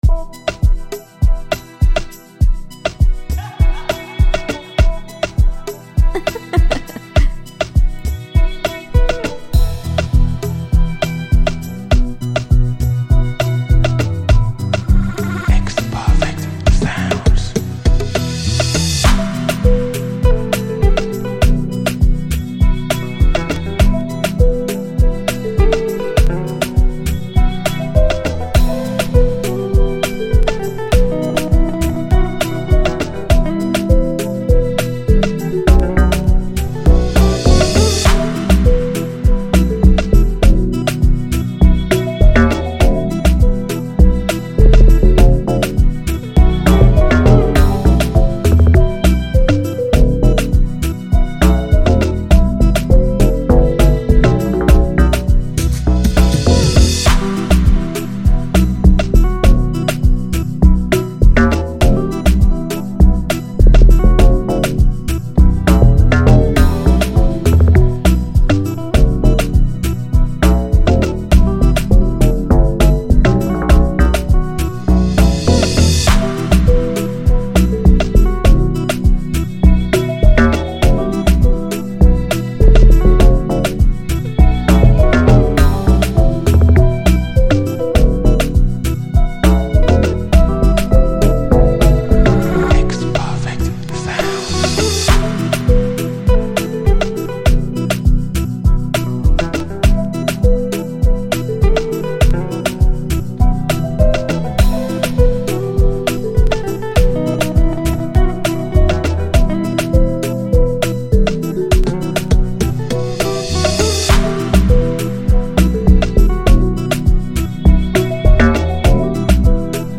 free beat instrumental